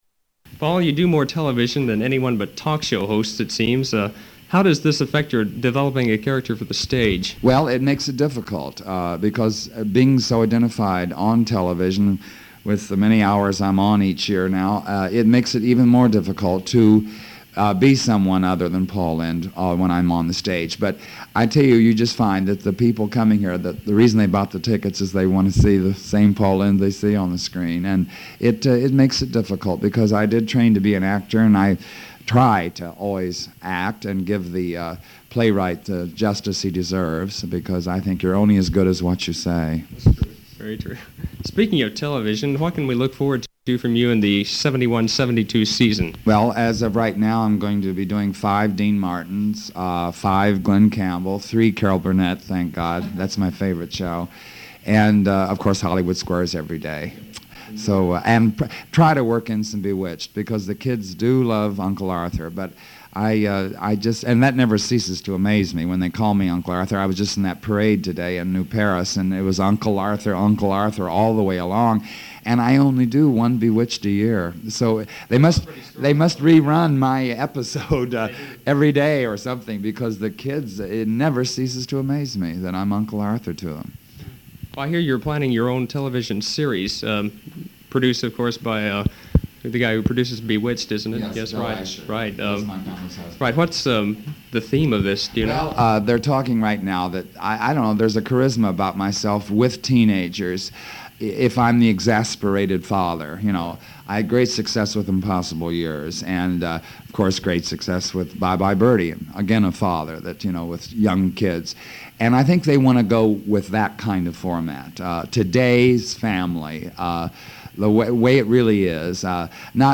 Interview July, 1971 part 3
Category: Comedians   Right: Personal